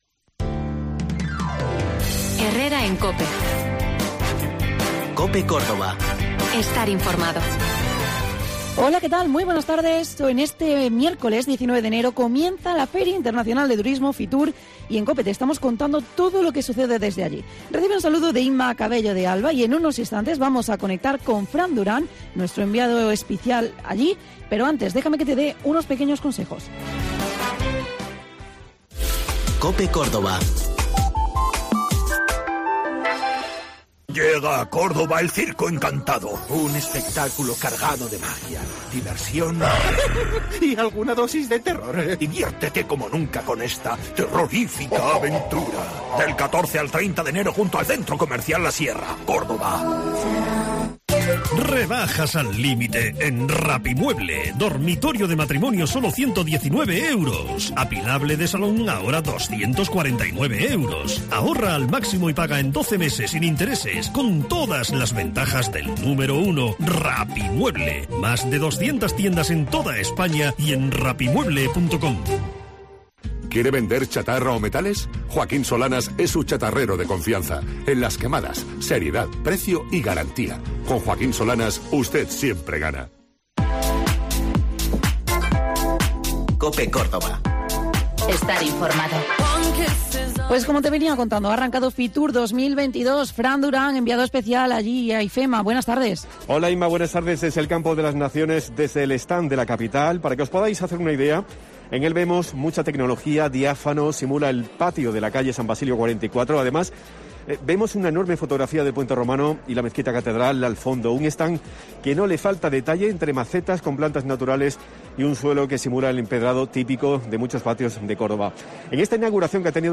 Hoy nos vamos hasta IFEMA donde te vamos a contar todo lo relativo a FITUR: Entrevistamos a Isabel Albás